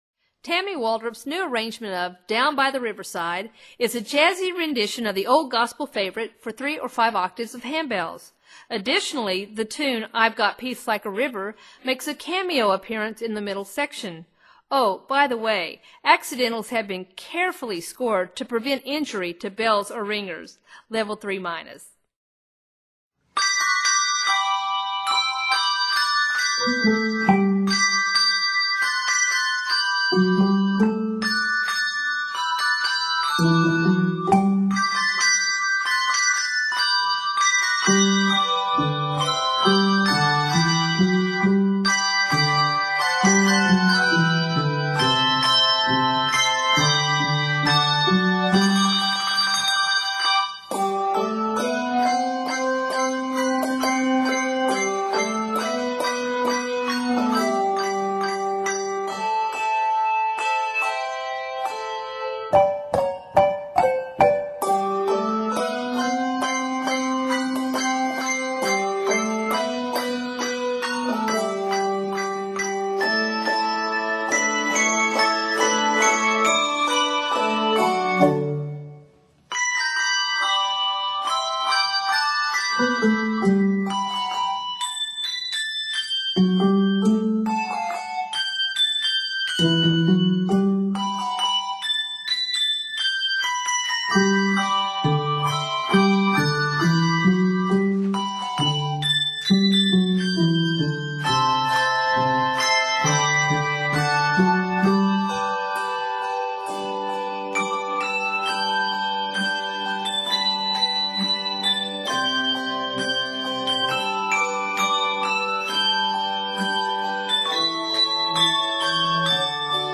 This jazzy arrangement of the old gospel hymn